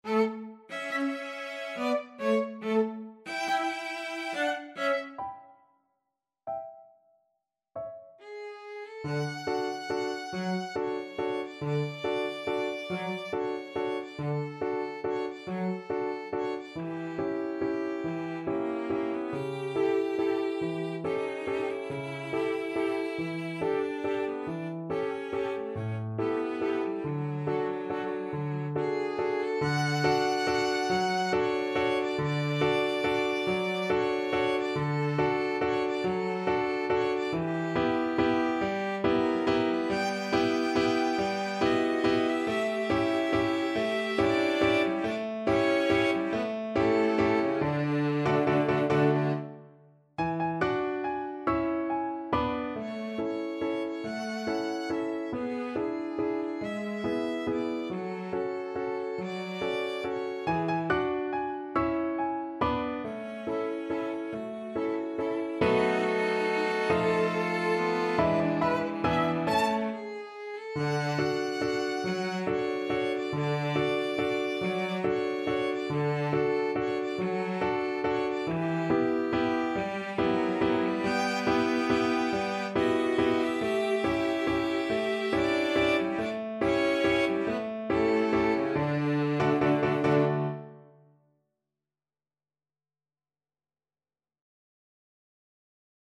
~ = 140 Tempo di Valse
3/4 (View more 3/4 Music)
Piano Trio  (View more Intermediate Piano Trio Music)
Classical (View more Classical Piano Trio Music)